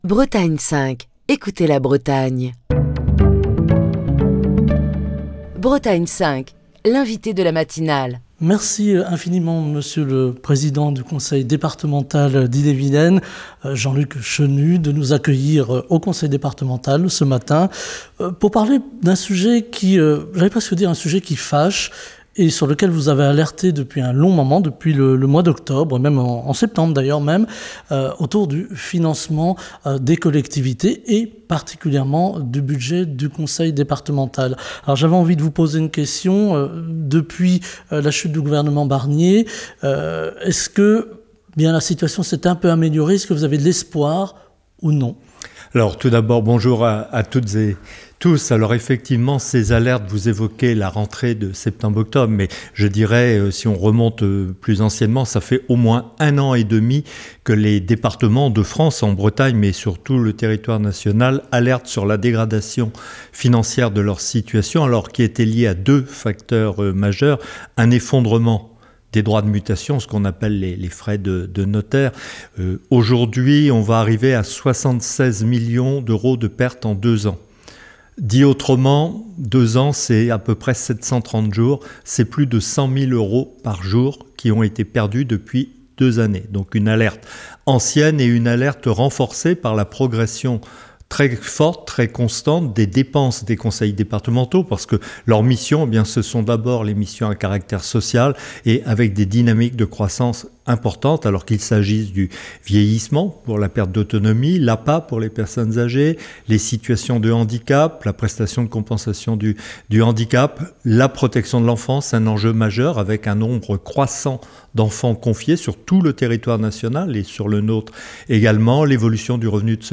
Ce matin, nous abordons la question des finances départementales avec Jean-Luc Chenut, président du Conseil départemental d'Ille-et-Vilaine. Invité de la matinale de Bretagne 5, il nous accueille dans son bureau au Conseil départemental pour partager son analyse de la situation économique et budgétaire du département.